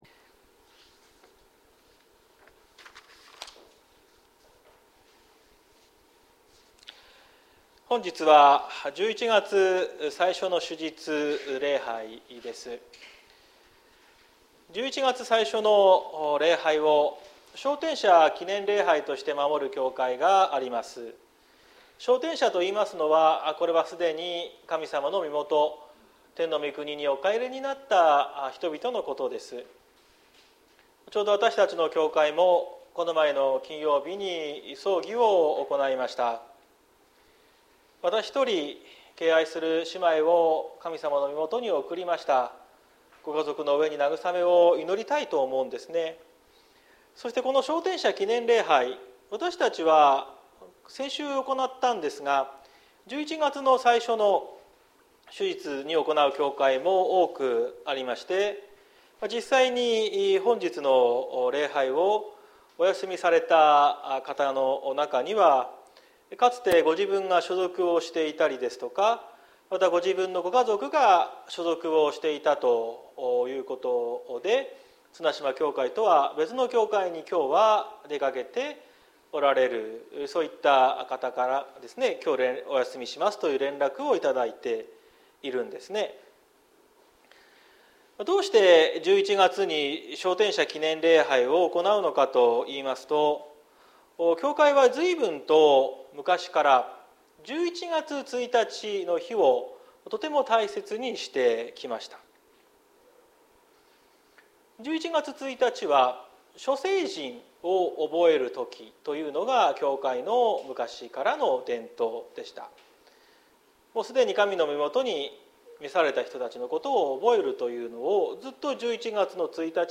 2023年11月05日朝の礼拝「キリストに受け入れられる」綱島教会
説教アーカイブ。